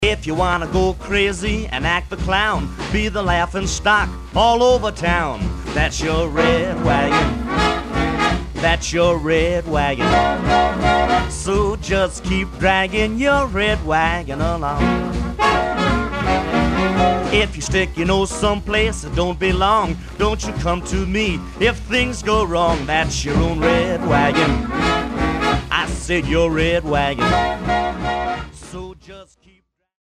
Rhythm & Blues